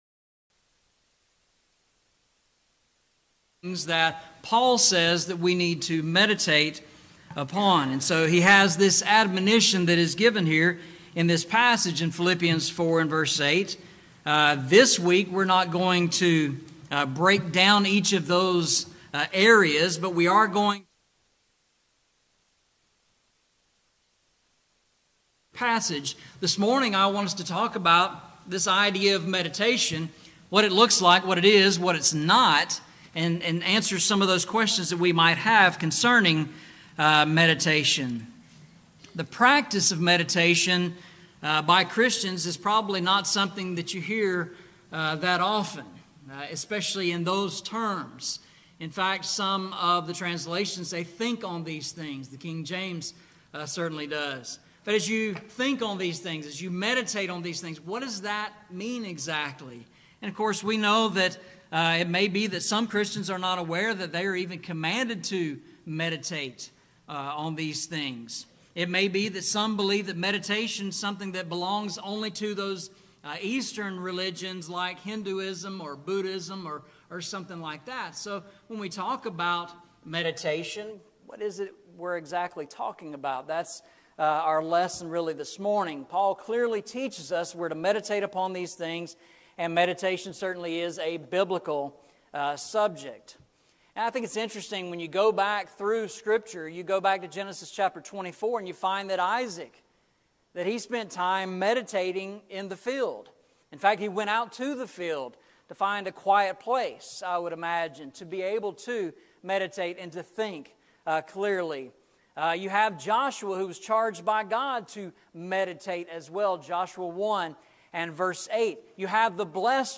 Series: Eastside Sermons
Philippians 4:8 Service Type: Sunday Morning « Faith Victorious Over Grief Caleb